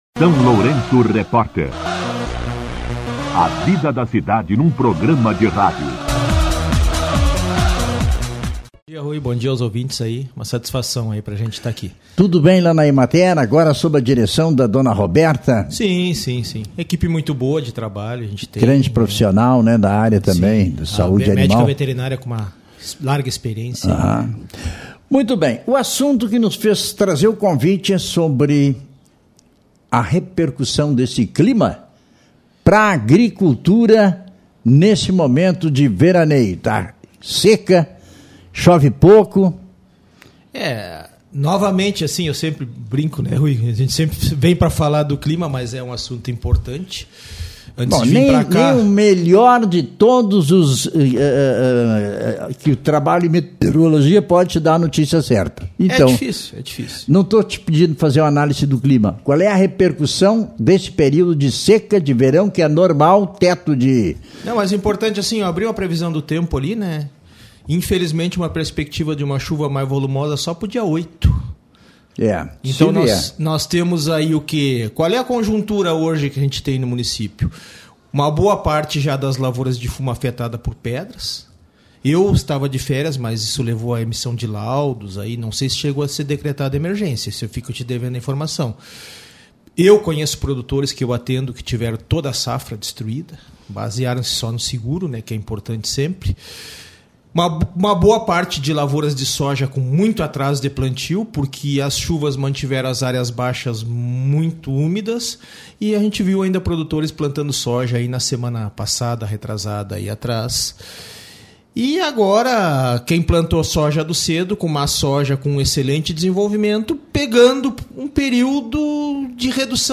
Entrevista com Eng.